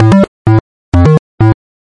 基调舞蹈A2 f2 128 bpm 3
描述：bassline dance a2 f2 128 bpm 3.wav
Tag: 最小 狂野 房屋 科技 配音步 贝斯 精神恍惚 舞蹈 俱乐部